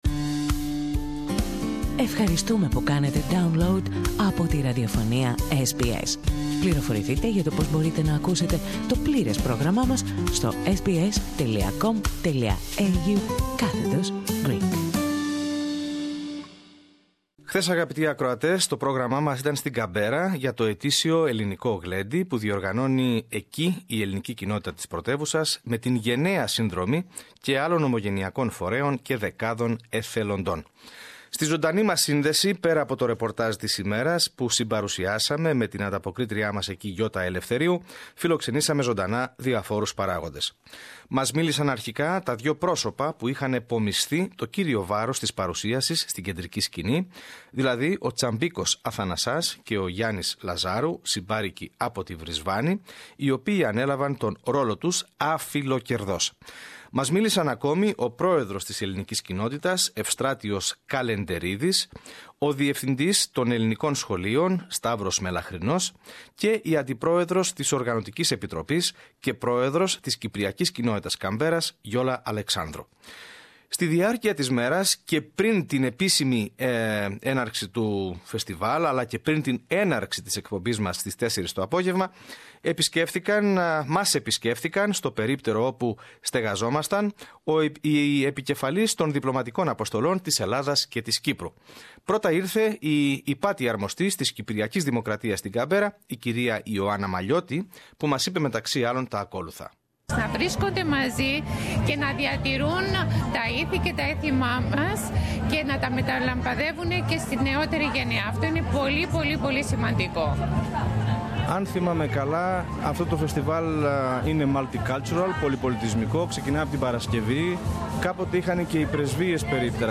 Στην επιτυχία που σημείωσε το «Ελληνικό Γλέντι» της Καμπέρας αναφέρθηκαν μιλώντας στο μικρόφωνο του Ελληνικού Προγράμματος της Ραδιοφωνίας SBS οι επικεφαλής των διπλωματικών αποστολών της Ελλάδας και της Κύπρου.